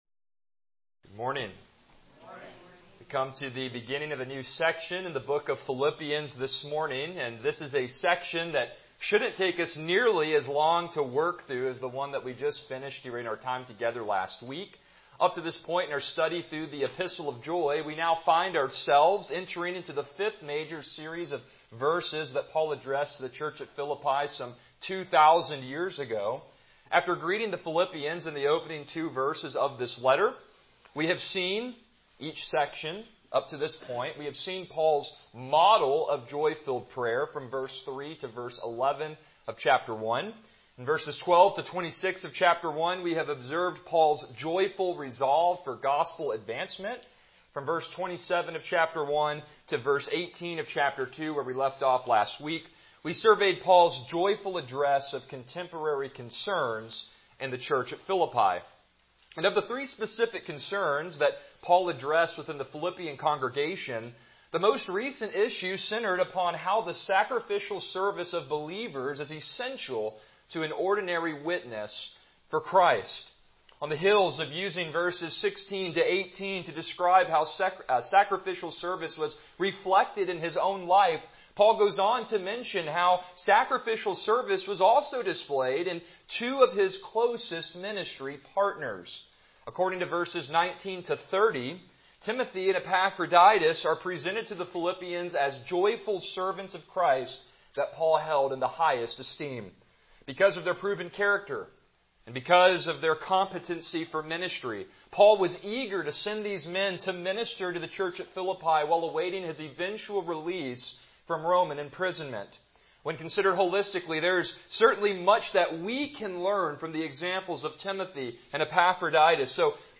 Passage: Philippians 2:19-24 Service Type: Morning Worship